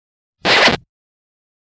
На этой странице собраны аутентичные звуки виниловых пластинок: характерные потрескивания, теплый аналоговый звук и шумы, создающие особую атмосферу.
Звук скретча и где его найти на сайте